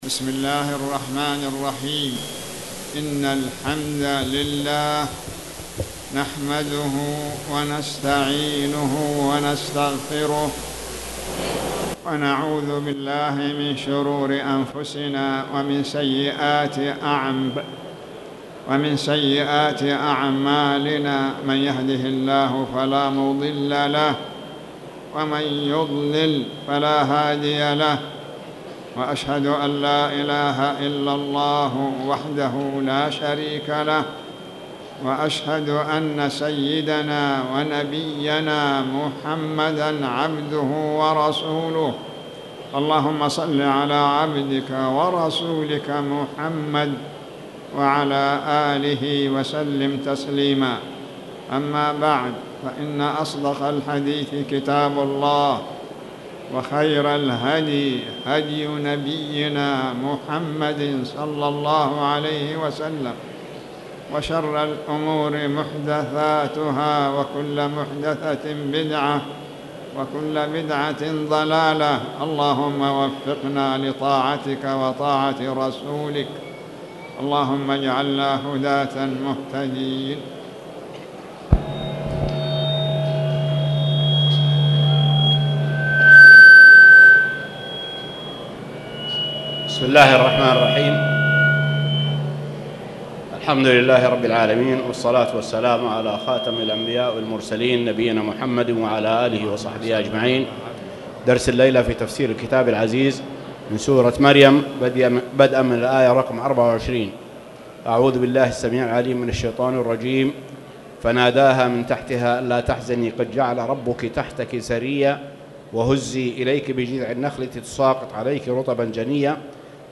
تاريخ النشر ٢٢ ربيع الأول ١٤٣٨ هـ المكان: المسجد الحرام الشيخ